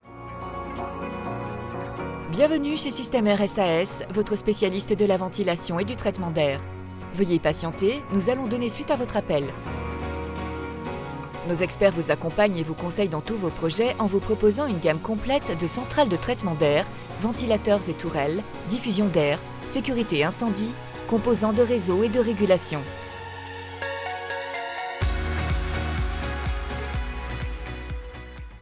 IVR, corporate, sérieuse, droite